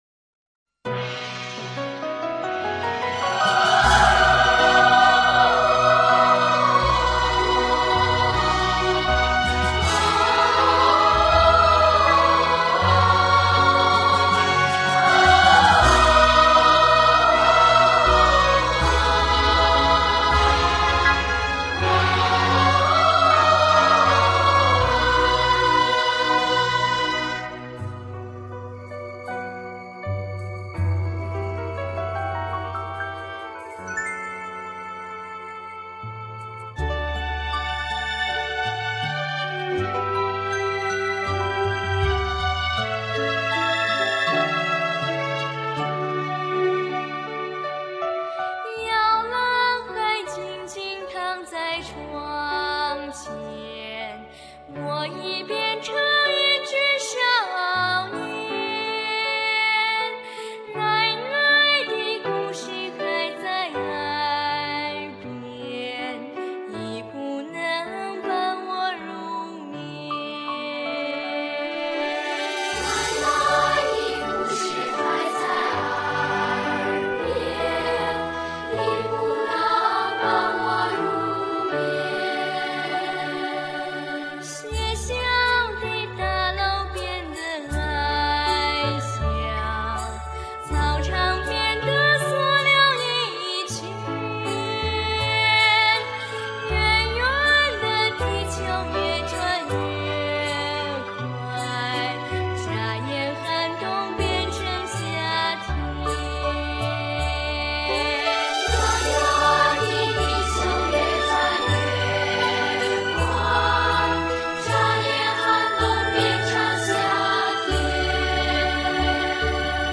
[25/12/2017]领唱、合唱《太阳少年》